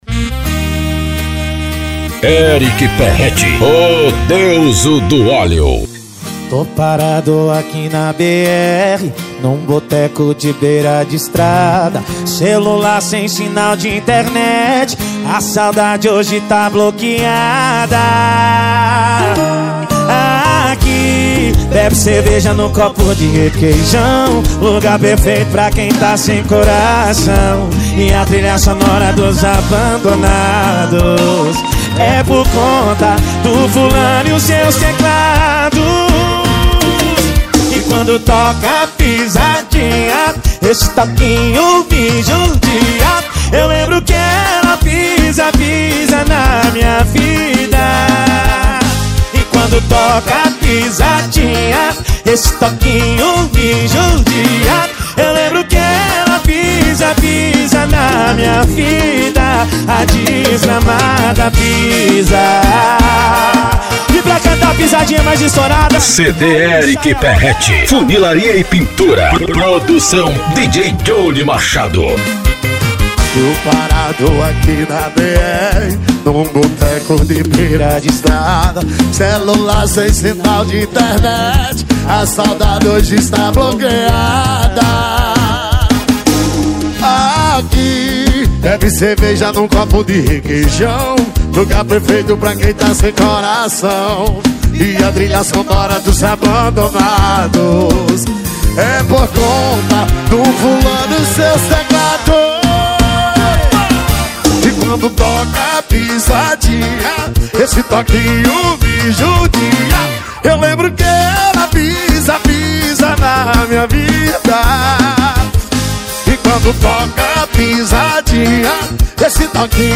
Sertanejo Universitário